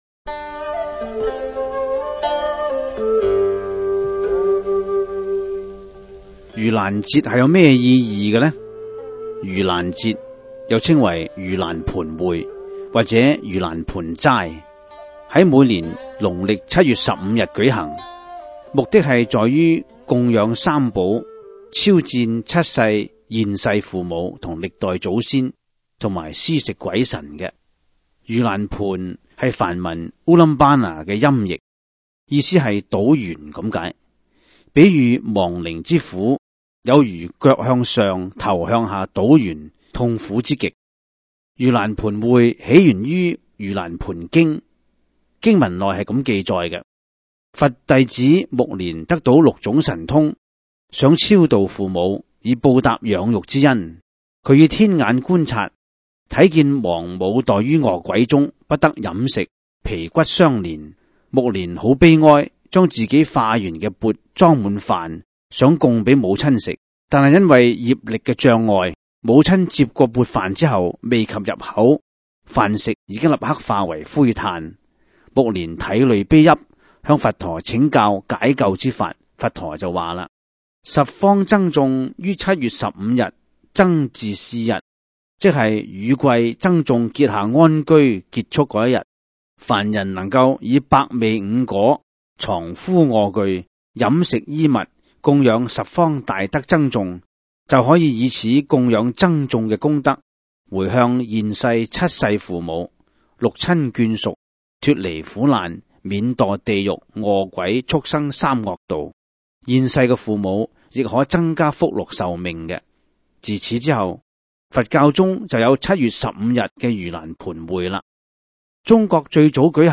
第 二 十 辑    (粤语主讲  MP3 格式)